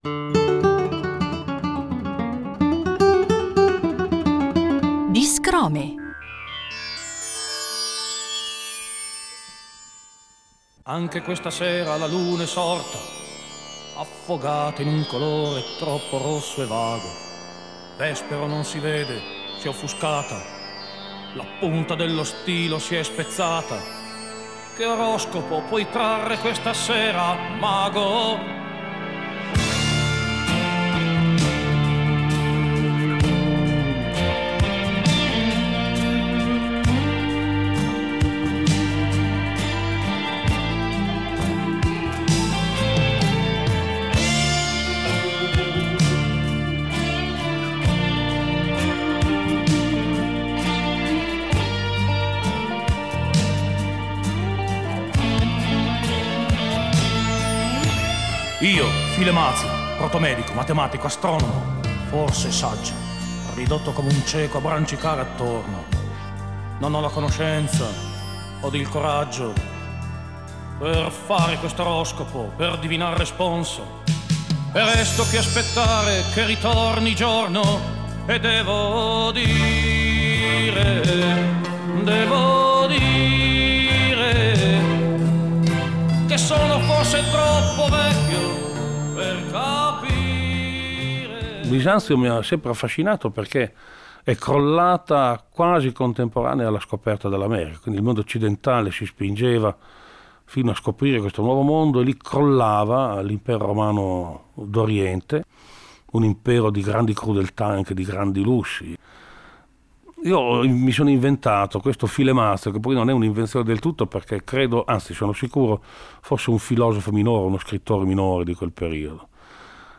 Una preziosa serie di chicche tratte dai nostri archivi: il grande cantautore emiliano Francesco Guccini introduce a modo suo alcune delle sue canzoni più note e amate dal pubblico.